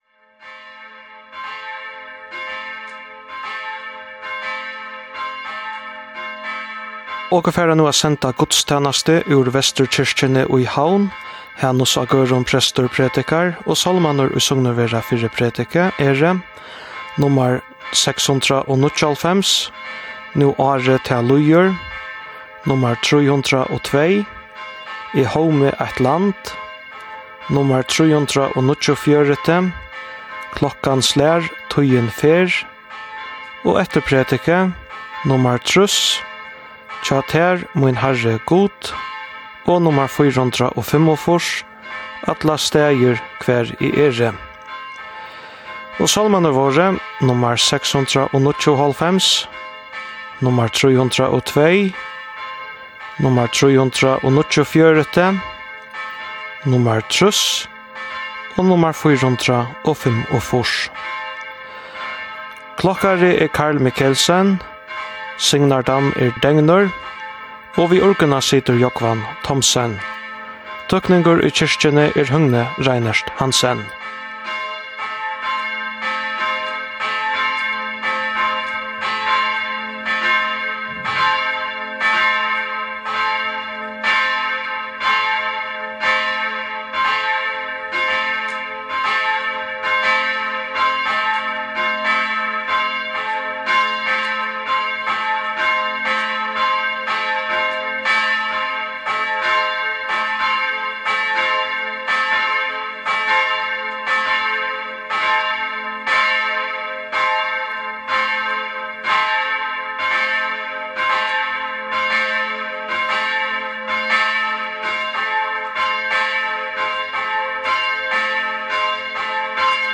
Guðstænastur í útvarpi
Útvarpið sendir beinleiðis gudstænastu hvønn sunnudag í árinum úr føroysku fólkakirkjuni.